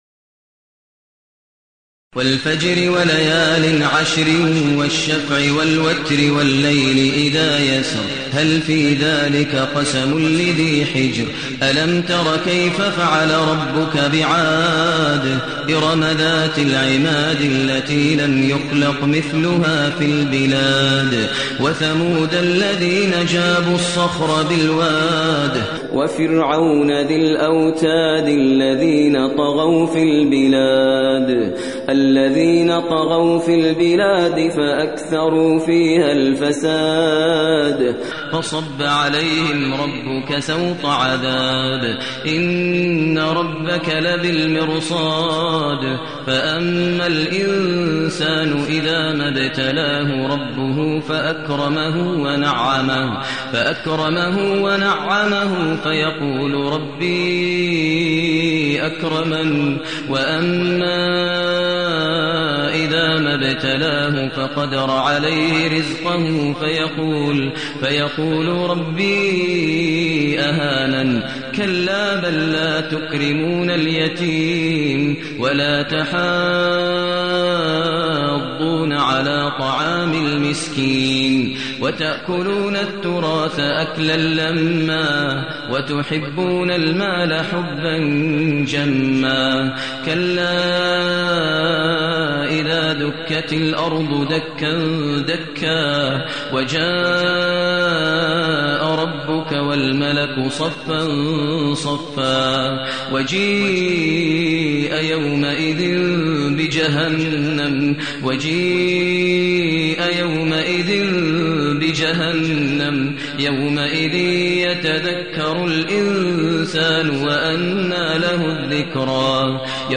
المكان: المسجد الحرام الشيخ: فضيلة الشيخ ماهر المعيقلي فضيلة الشيخ ماهر المعيقلي الفجر